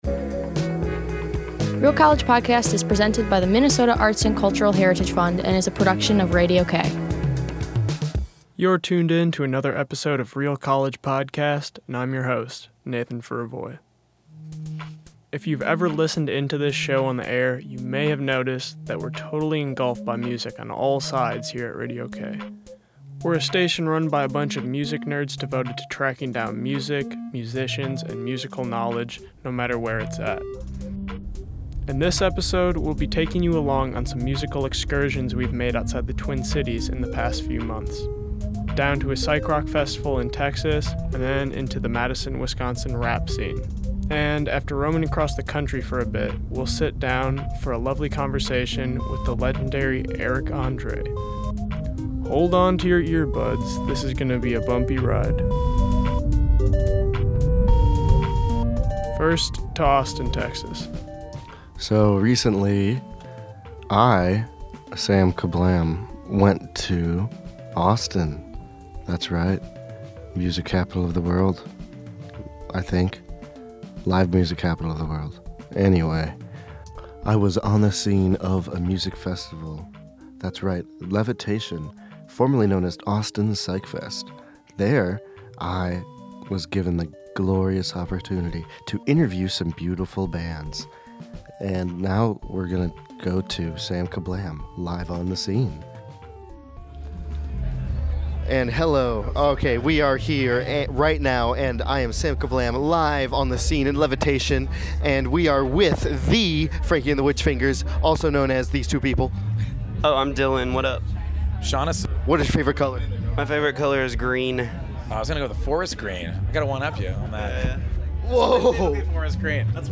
Ride along with RCP as we interview some musicians from around the country before sitting down to chat with comedian Eric Andre. 1:00